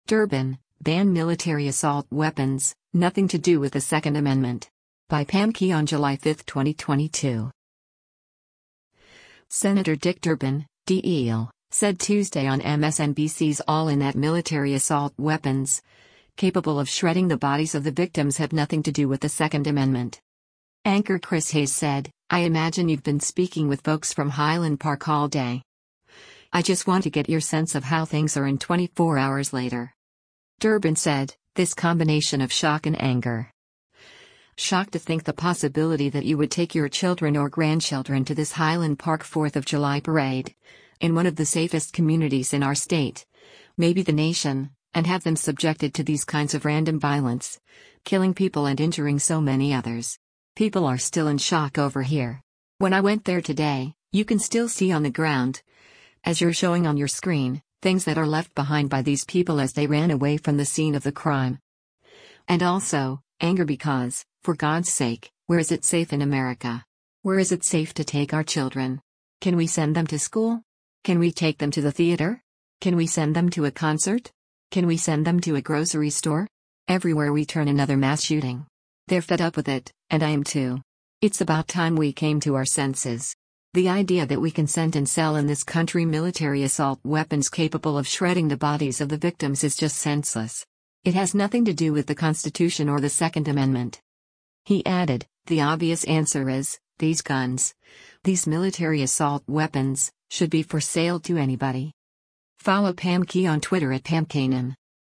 Senator Dick Durbin (D-IL) said Tuesday on MSNBC’s “All In” that “military assault weapons, capable of shredding the bodies of the victims” have nothing to do with the Second Amendment.